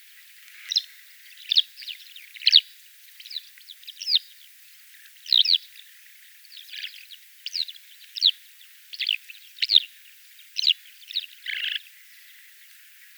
Alauda arvensis - Skylark - Allodola
DATE/TIME: 30/november/2021 (3 p.m.) - IDENTIFICATION AND BEHAVIOUR: Four Skylarks fly off from a cereal field and fly around me in circles. Lowland with cultivated fields. - POSITION: Ponti di Badia, Castiglione della Pescaia, LAT. N. 42°46'/LONG. E 10°59' - ALTITUDE: 0 m. - VOCALIZATION TYPE: flight calls - SEX/AGE: unknown - COMMENT: there are at least four different call types. The birds didn't seem alarmed by my presence. - MIC: (P with Tascam DR100-MKIII)